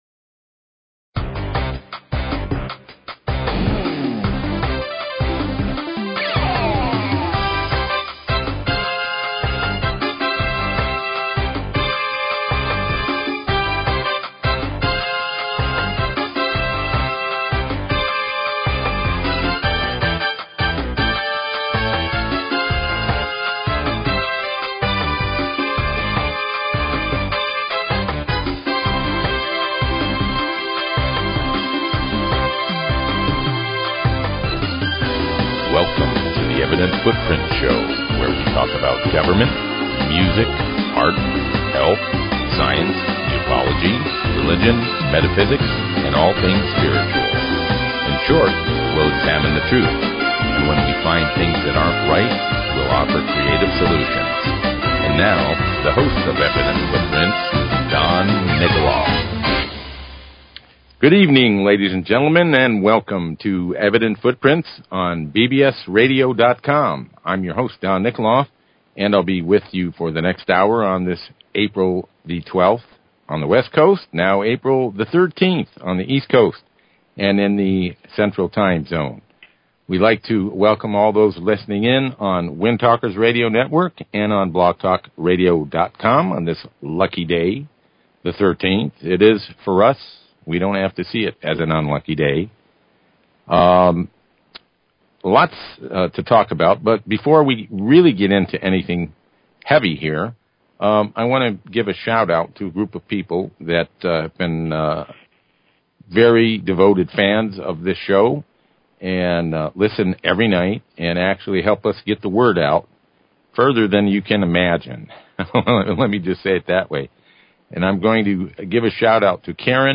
Talk Show Episode, Audio Podcast, Evident_Footprints and Courtesy of BBS Radio on , show guests , about , categorized as
Chemtrail Opposition Researcher